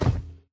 mob / irongolem / walk2.ogg
walk2.ogg